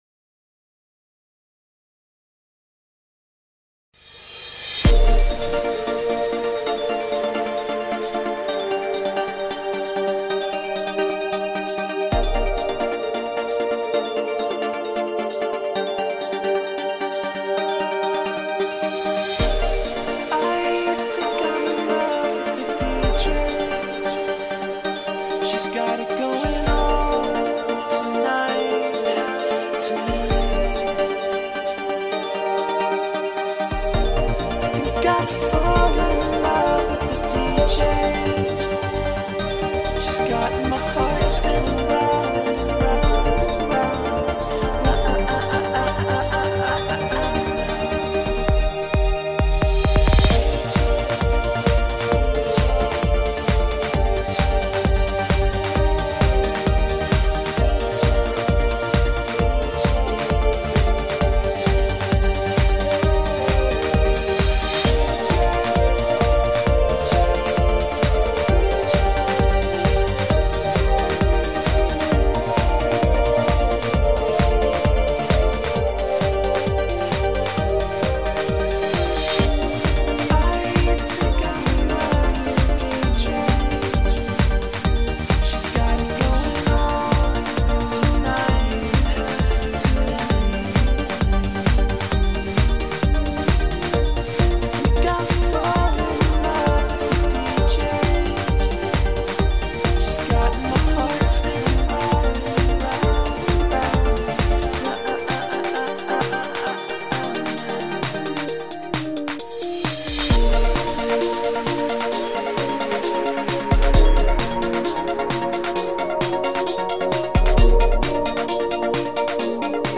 a love song